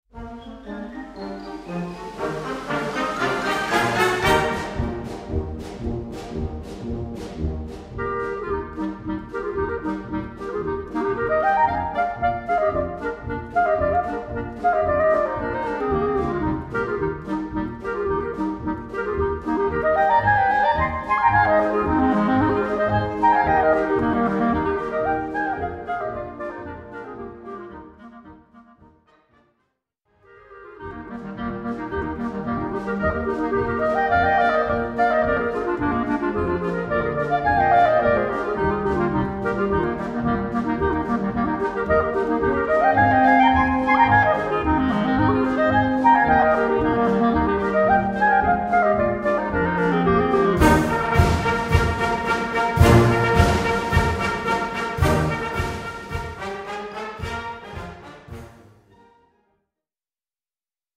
Solo für 2 Klarinetten
Besetzung: Blasorchester